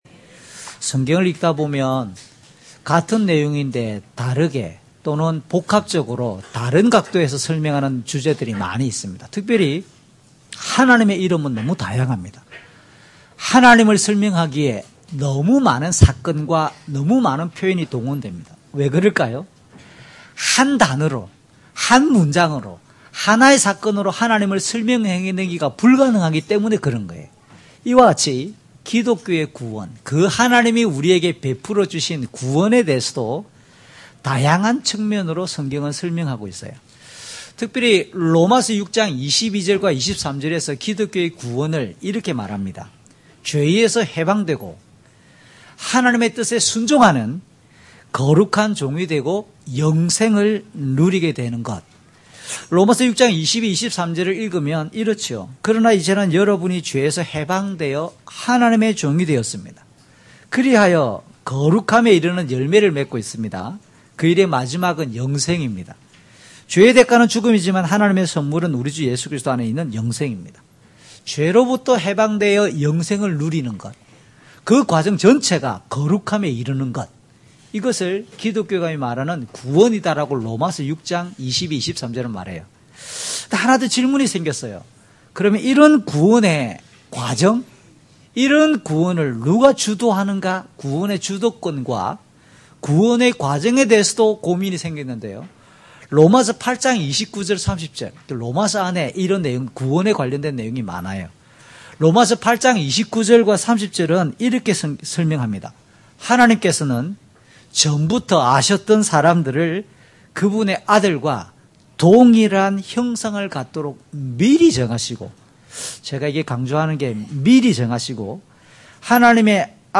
주일설교 - 2018년 02월 11일 "교회, 은혜로 부름받은 거룩한 공동체입니다!"(고전1:1-9)